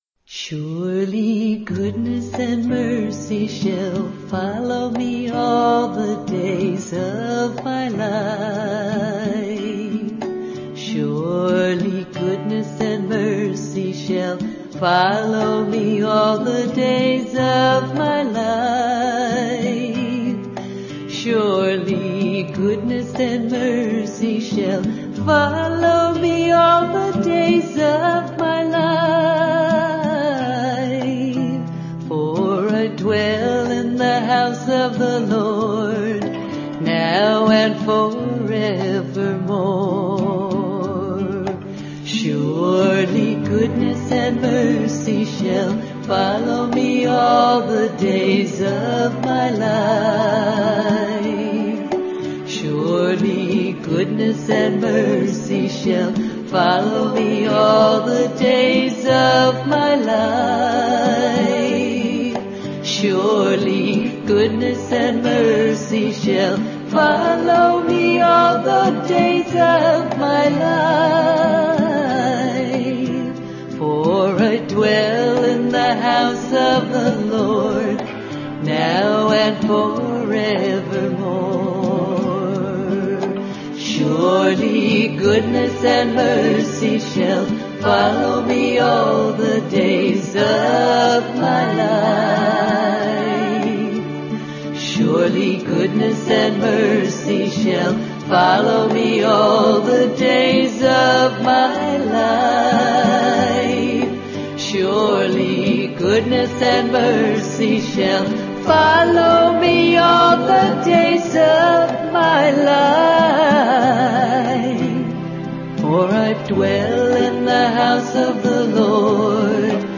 1. Devotional Songs
Major (Shankarabharanam / Bilawal)
8 Beat / Keherwa / Adi
Medium Slow